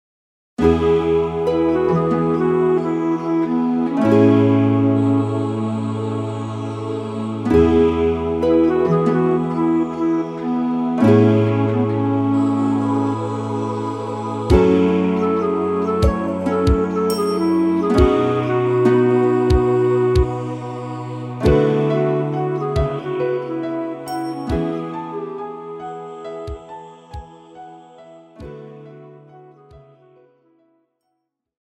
KARAOKE/FORMÁT:
Žánr: Pop